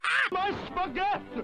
Play, download and share aah, my spaggett original sound button!!!!